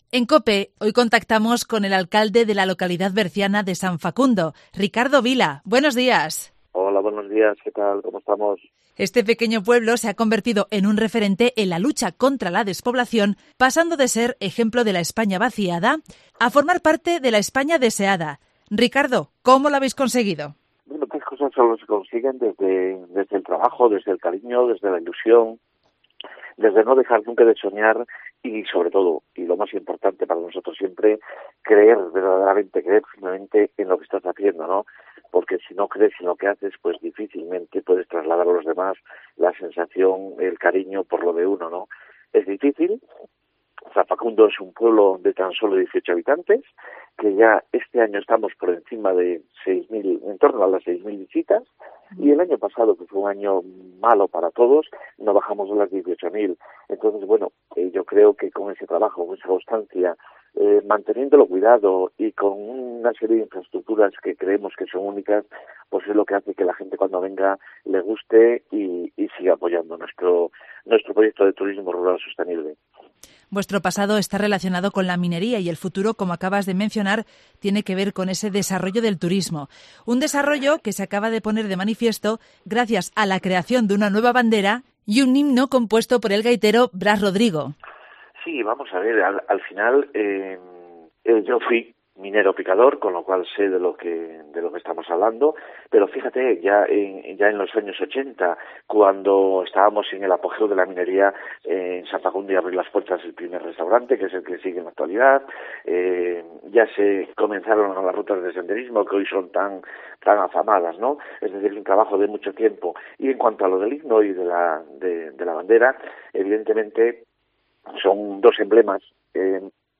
En Cope contactamos con el alcalde de San Facundo en el Bierzo, Ricardo Vila, porque la localidad, que cuenta con tan sólo 18 habitantes, es un referente en la lucha contra la despoblación y aunque ligada a un pasado mireno, sabe adaptarse a los tiempos y buscar otras vías de desarrollo, tanto es así que hace unos días San Facundo presentó públicamente una nueva bandera y un himno que “refuerzan su identidad institucional” .La bandera permanece izada en el mirador de San Facundo